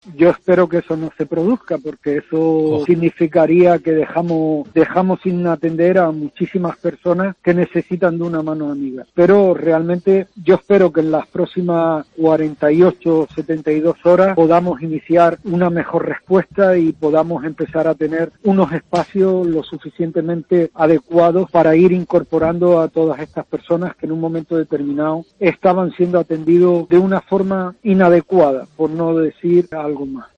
ha asegurado en los micrófonos de COPE Gran Canaria estar preocupado por la incesante llegada de personas y el trato “inhumano” que se le está dando por parte de las administraciones.